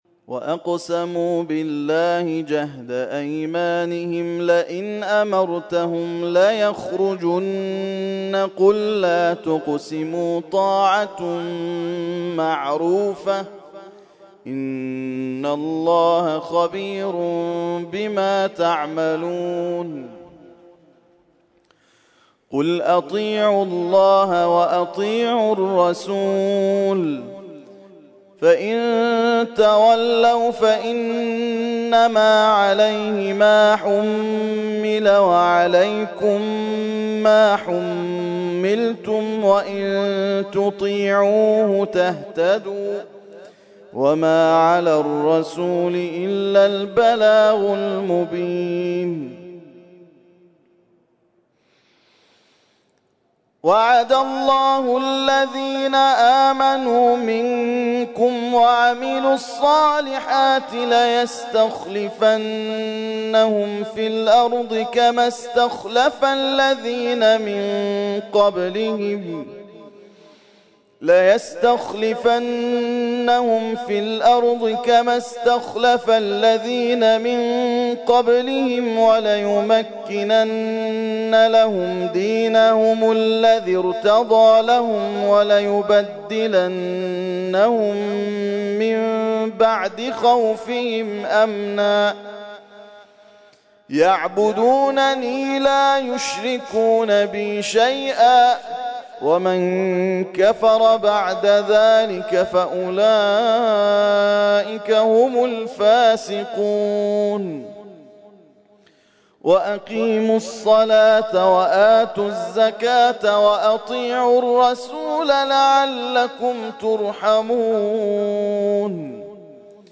ترتیل خوانی جزء ۱۸ قرآن کریم در سال ۱۳۹۴